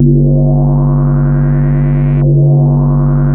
JUP 8 G3 9.wav